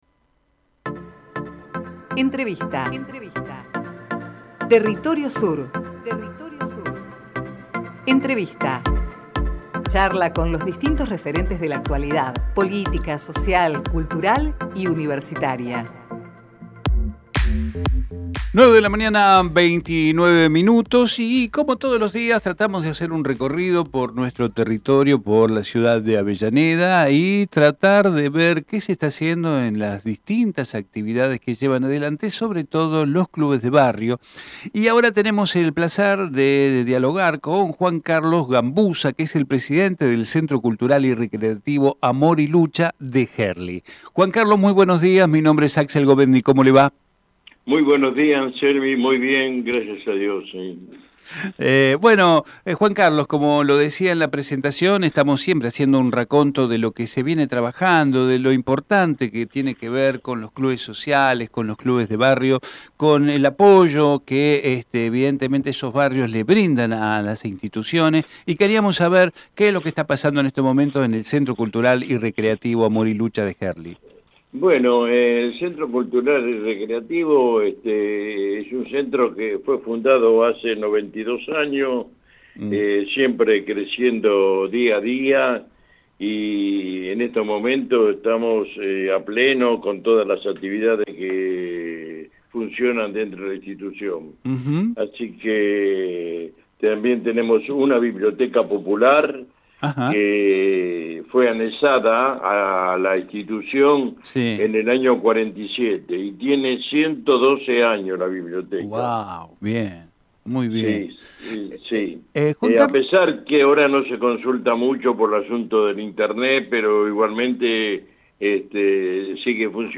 Compartimos la entrevista realizada en Territorio Sur